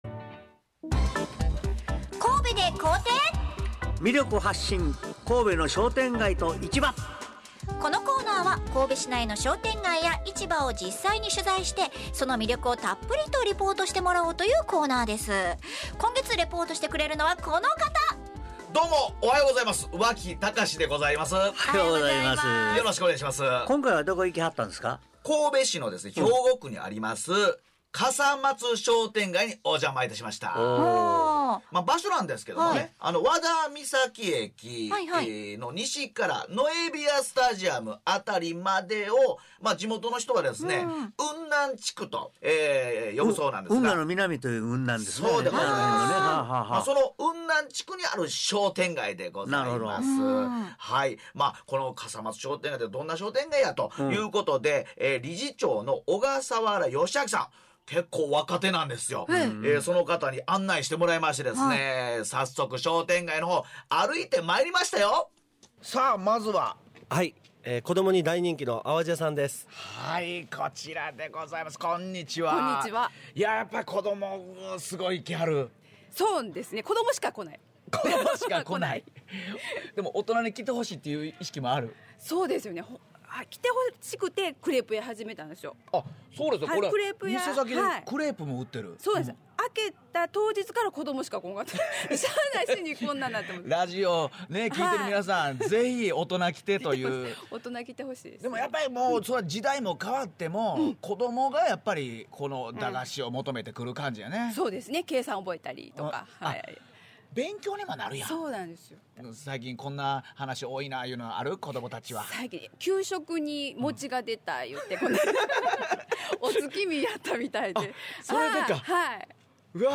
このコーナーは神戸市内の商店街や市場を実際に取材して･･ その魅力をたっぷりとリポートします。